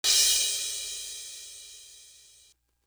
Crashes & Cymbals
Air It Out Crash.wav